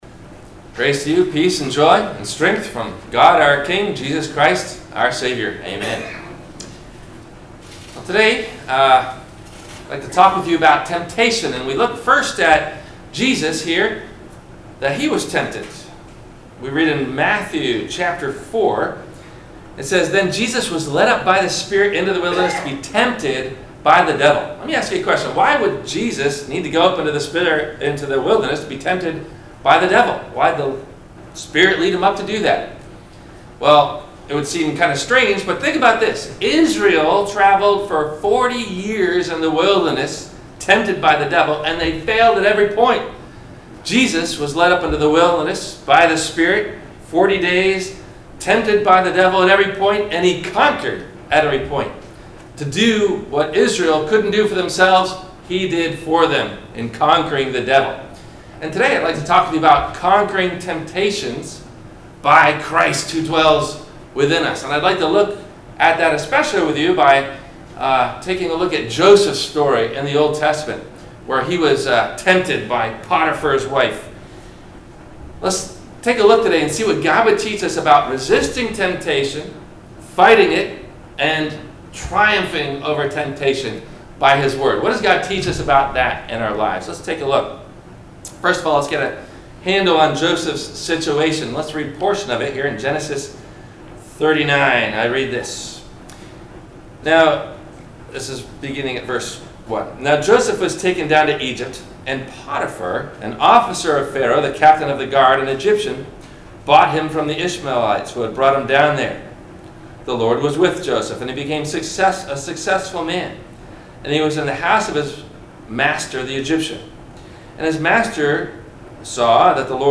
How To Conquer Temptation – Sermon – February 18 2018